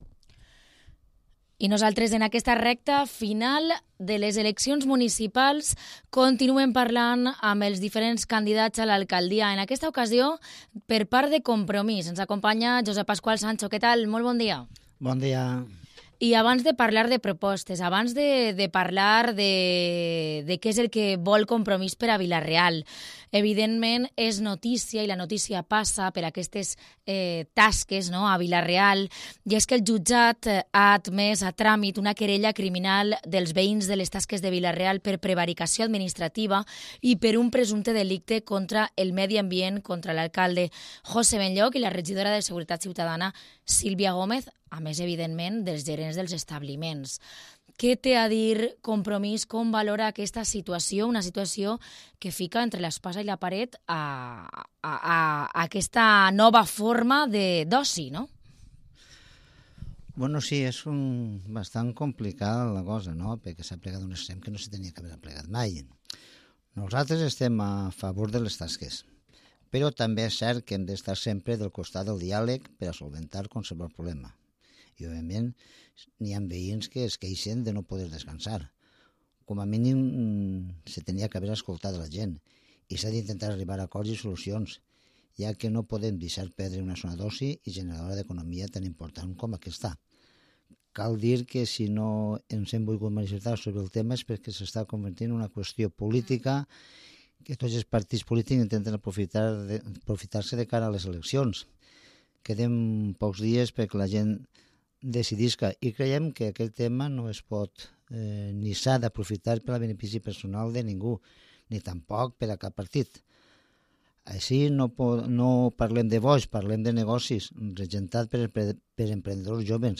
Entrevista política, Compromís